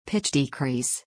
pitchdecrease.mp3